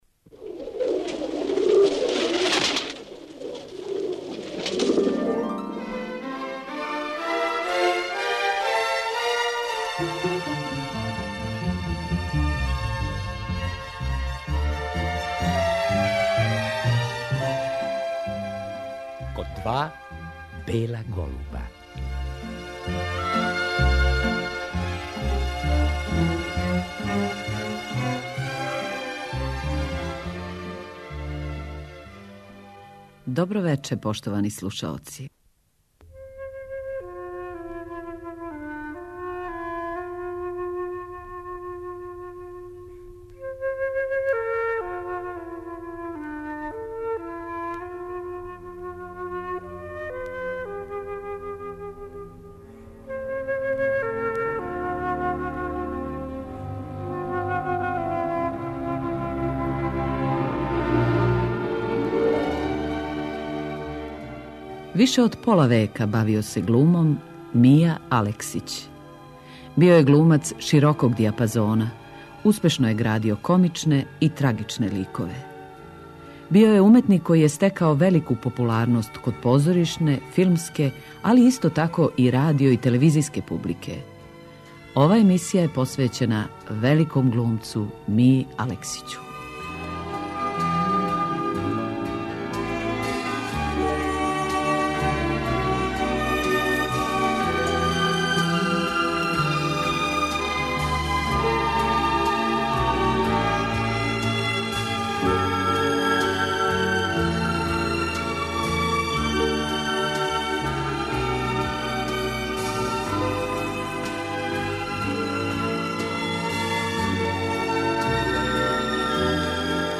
Мија Алексић је био гост ове емисије новембра 1993. године, некако одмах после снимања филма "Танго аргентино". Снимак тог разговара поново емитујемо.